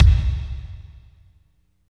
30.04 KICK.wav